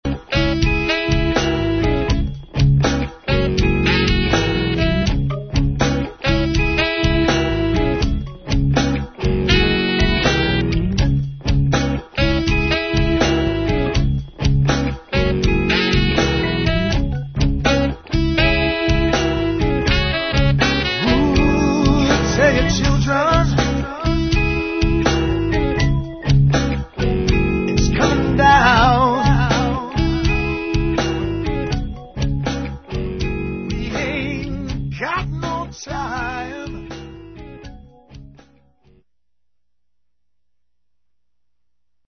Guitar
Vocals / Drums
Bass Guitar
Keyboards
Percussion